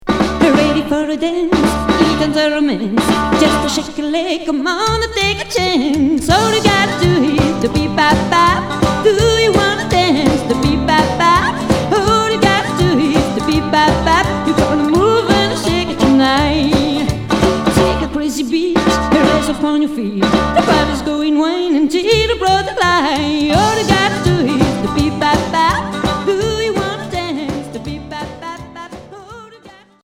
Rockabilly Deuxième 45t retour à l'accueil